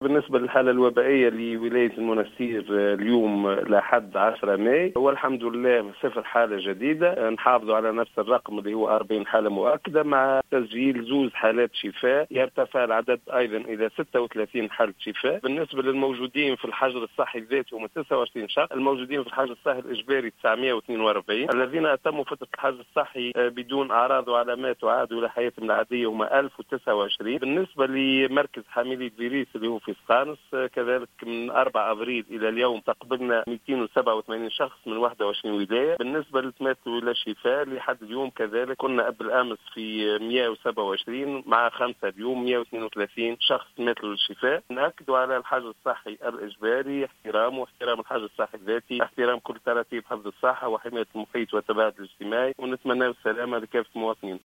وأضاف الهواني في تصريح للجوهرة أف-أم، أنه تمّ تسجيل حالتي شفاء لرجلين يبلغ الأول من العمر 45 سنة و الثاني 65 سنة أصيلا المنستير، ليرتفع عدد حالات الشفاء في الولاية الى 40 .